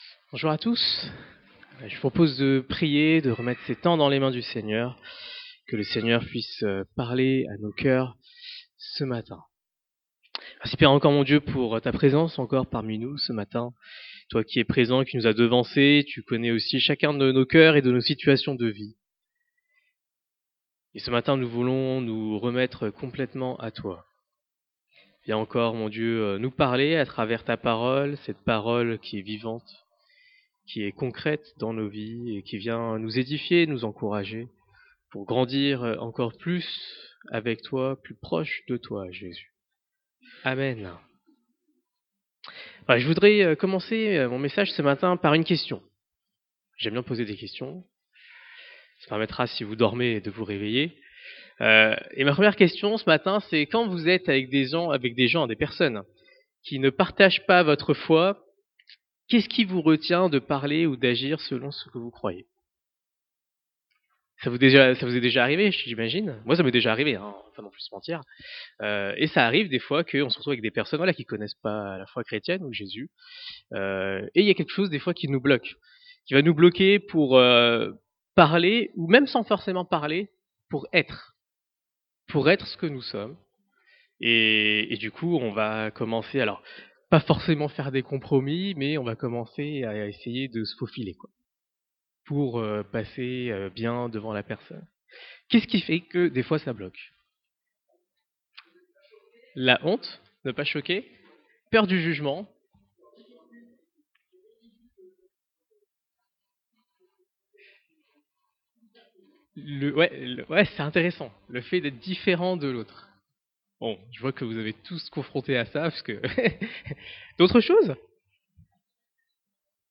2026 La peur du regard des autres Prédicateur